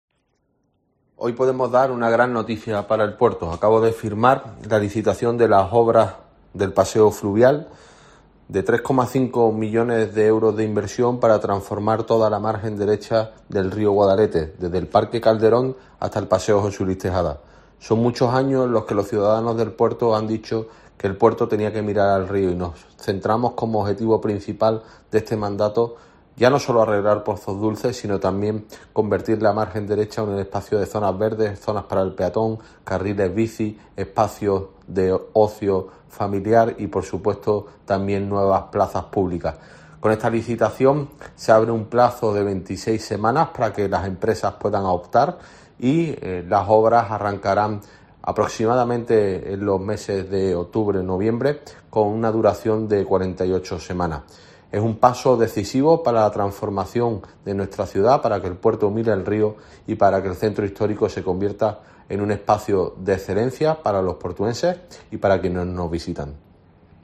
Germán Beardo, alcalde de El Puerto de Santa María (Cádiz)